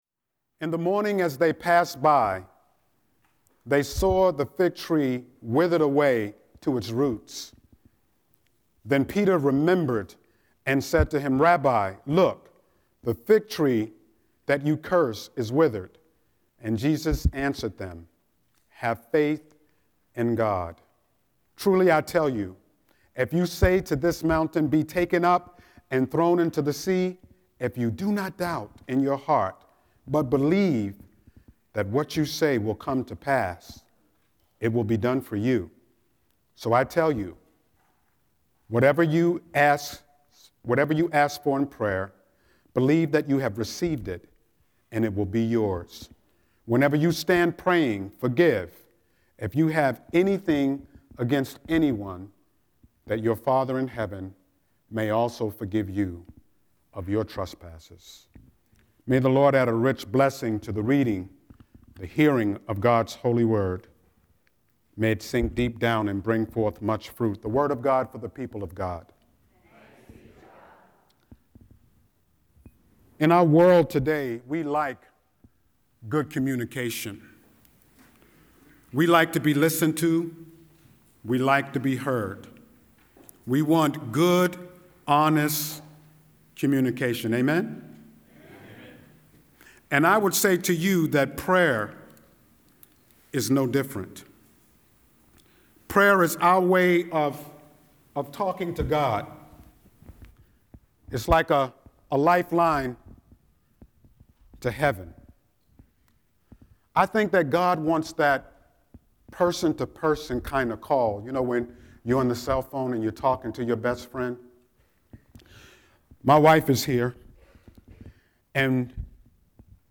09-06-Scripture-and-Sermon.mp3